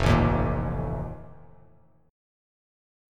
F11 Chord
Listen to F11 strummed